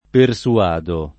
persuadere [ per S uad % re ; non - S u- # - ] v.; persuado [ per S u- # do ] — pass. rem. persuasi [ per S u- #@ i ] (ant. persuadetti [ per S uad $ tti ]); part. pass. persuaso [ per S u- #@ o ] — cfr. suadere